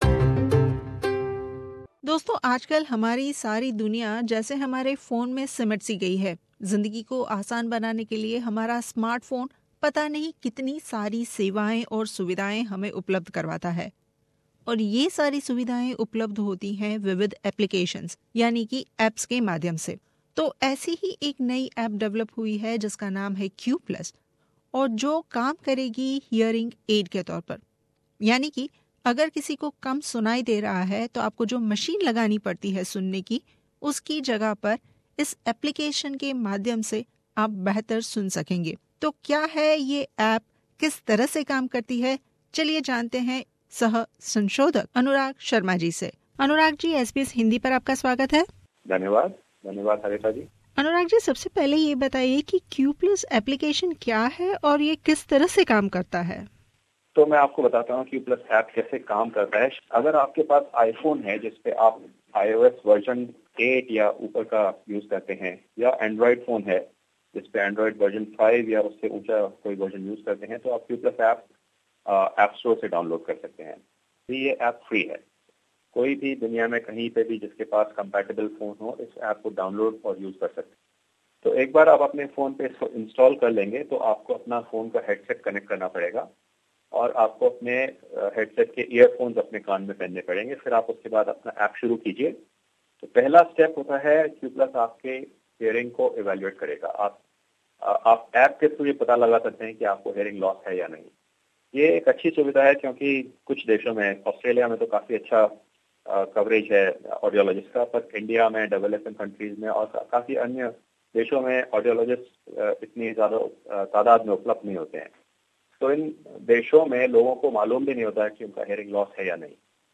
खास बातचीत